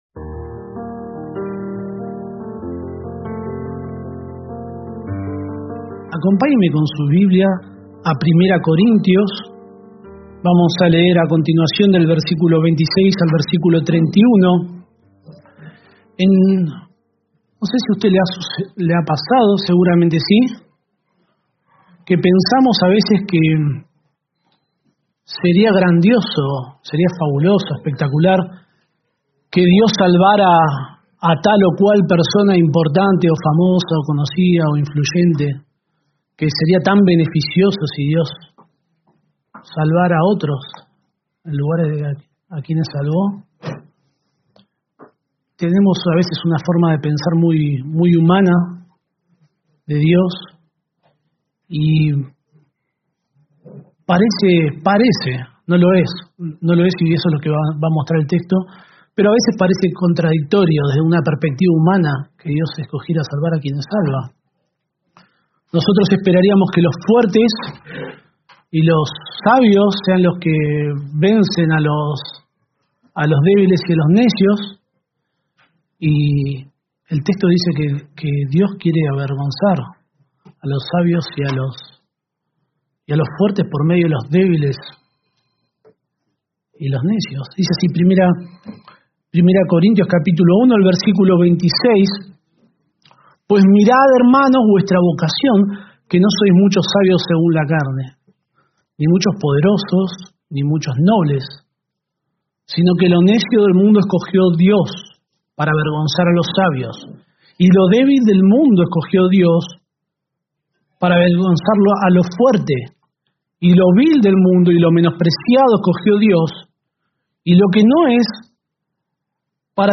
Reina-Valera 1960 (RVR1960) Video del Sermón Audio del Sermón Descargar audio Temas: